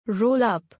roll-up.mp3